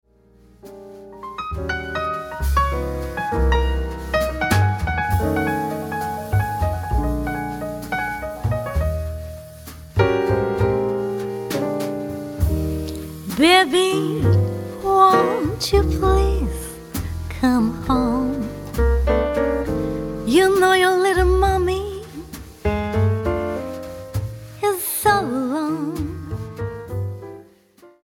voice
piano
bass
drums